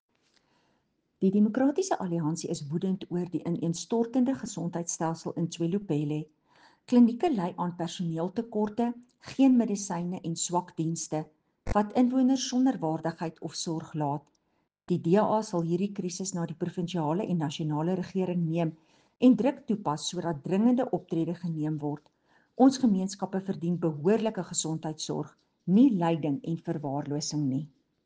Afrikaans soundbites by Cllr Estelle Pretorius and